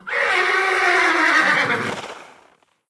c_whorse_dead.wav